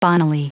Prononciation du mot bonnily en anglais (fichier audio)
Prononciation du mot : bonnily